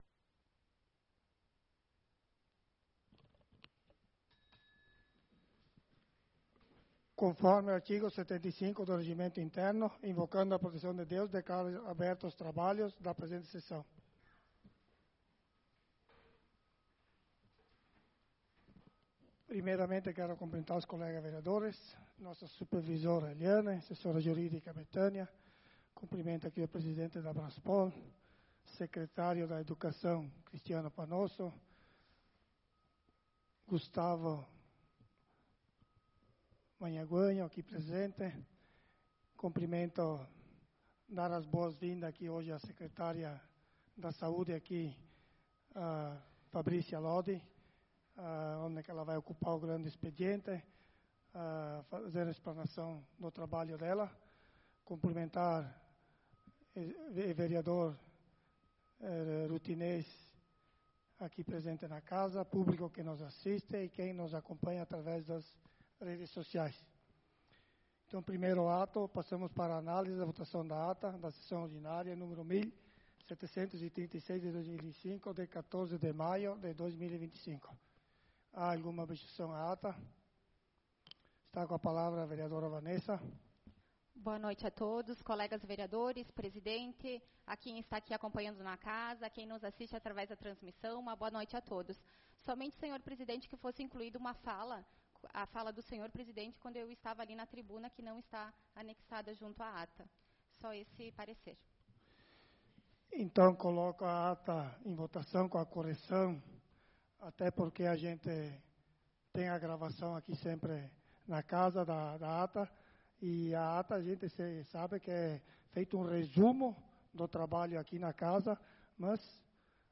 Sessão Ordinária do dia 21/05/2025